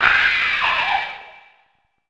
c_hunter_dead.wav